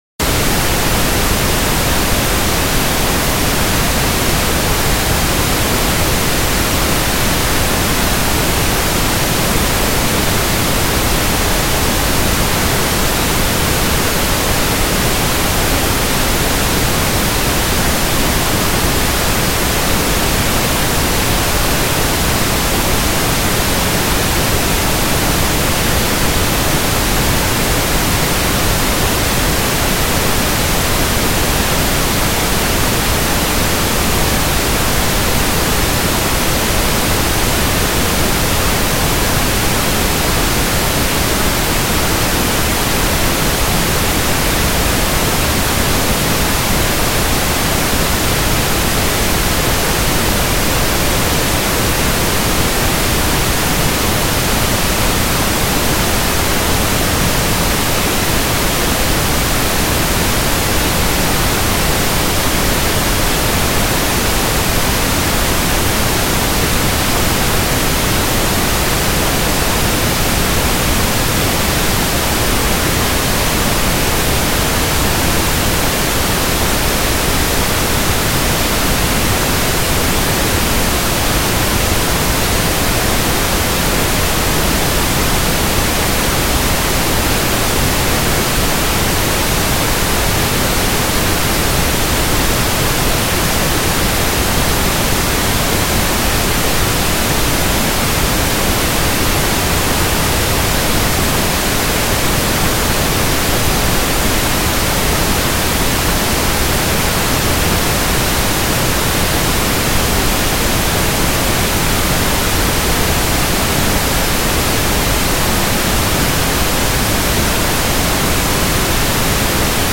La prueba Consistirá en: 30 segundos de Pink Noise (Sonido Rosa frecuencias de 1 a 20000hz) para obtener los DB,s y SQ del Sistema de sonido